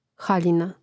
hàljina haljina